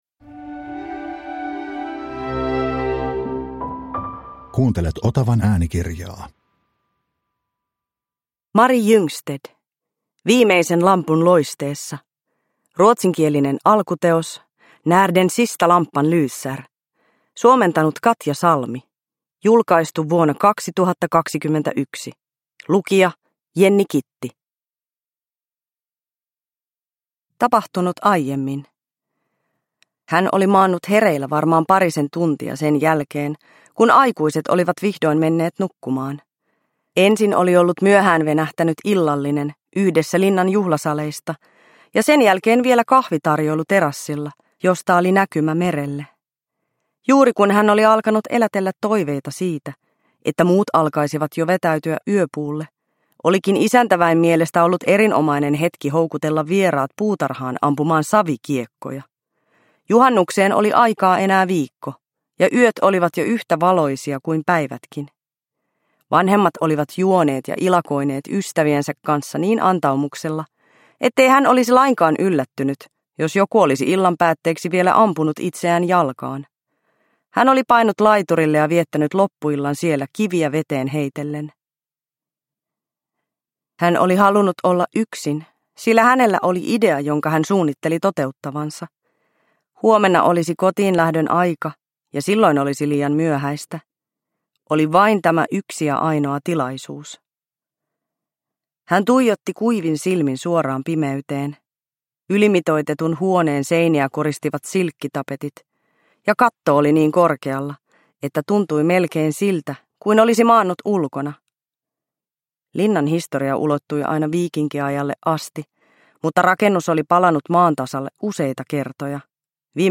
Viimeisen lampun loisteessa – Ljudbok – Laddas ner